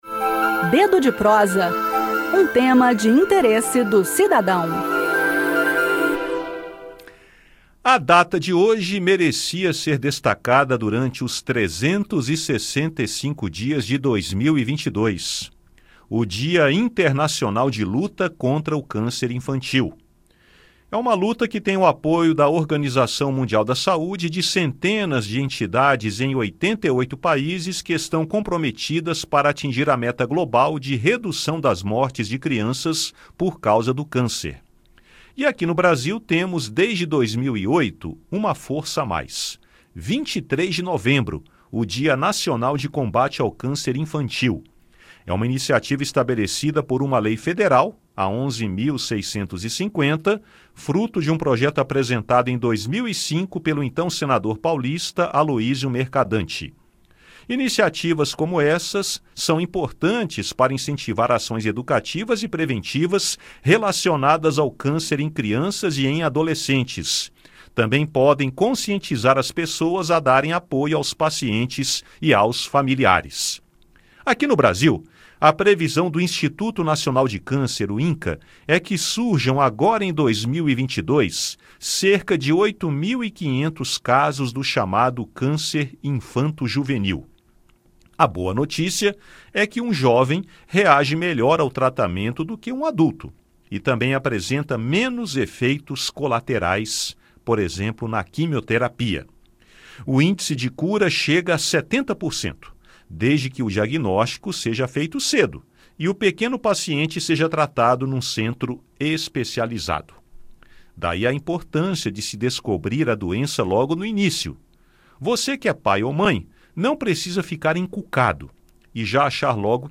O assunto do bate-papo de hoje é o Dia Internacional de Luta contra o Câncer Infantil.